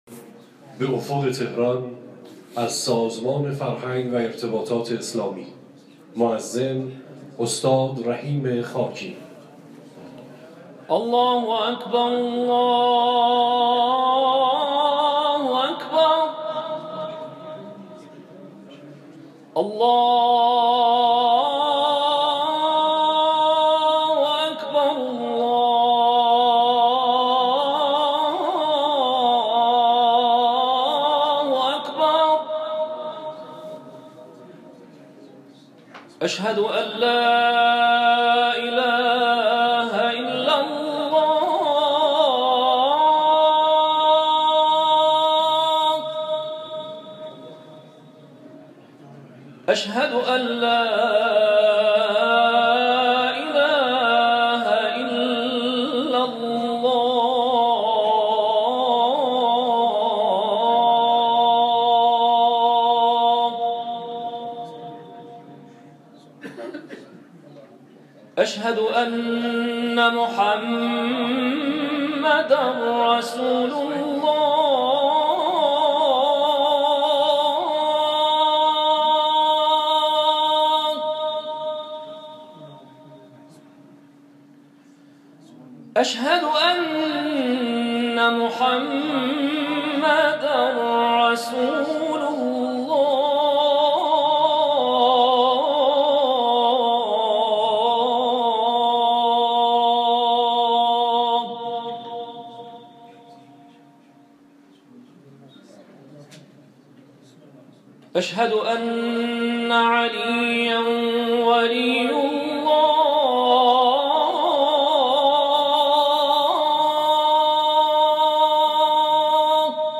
تلاوت اذانگاهی